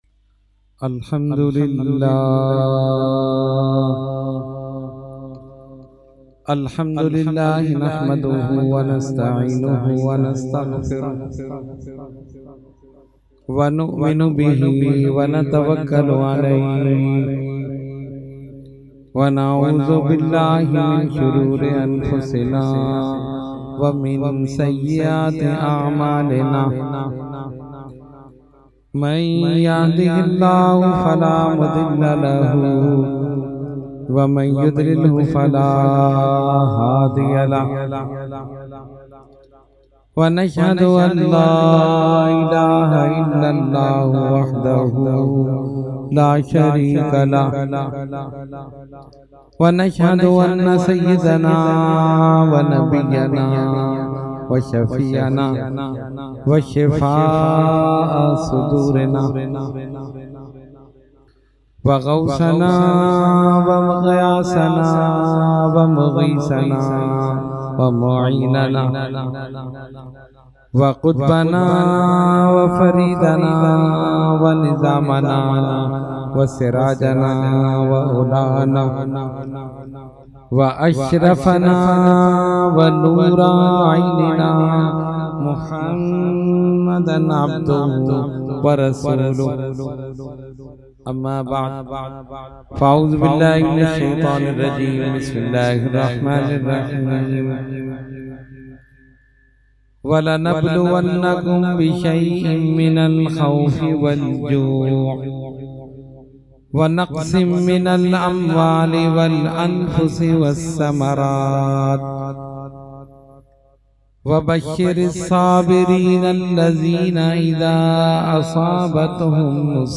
Category : Speech | Language : UrduEvent : Muharram 2019